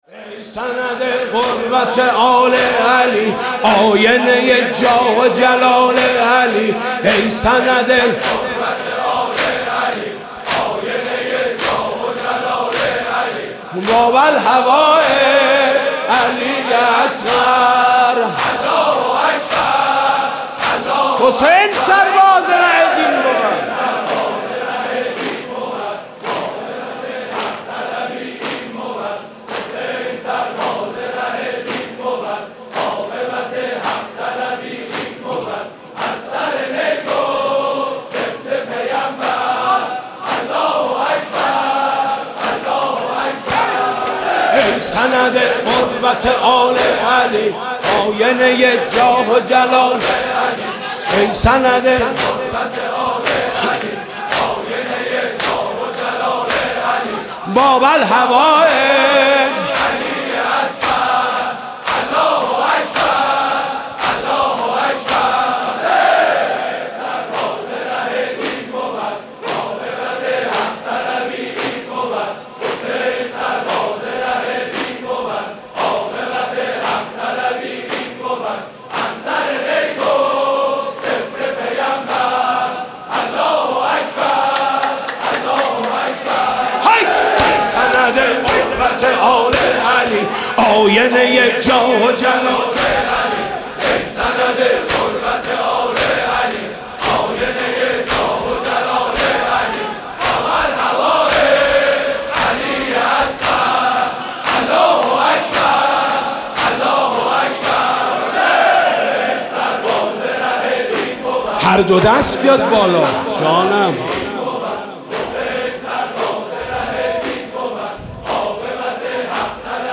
محرم 96 - دودمه - اي سند غربت آل علي